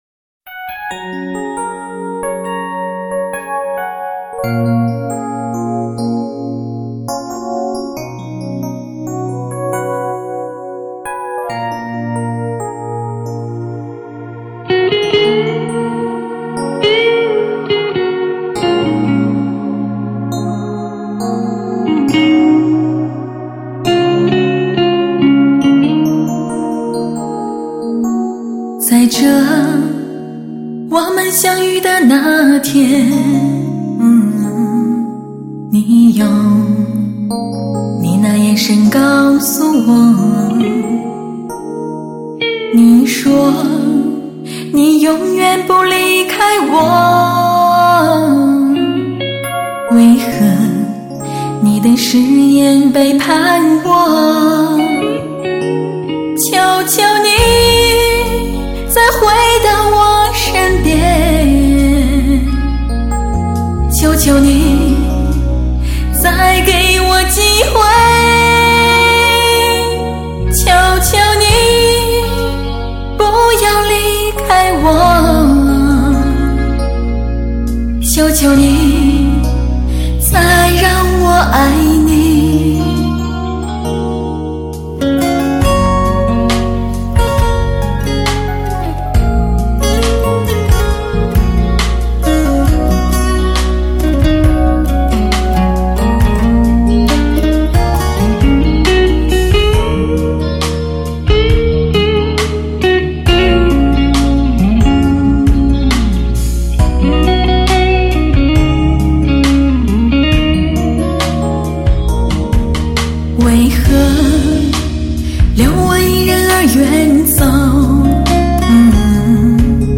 无法抗拒的纯粹女声
专辑格式：DTS-CD-5.1声道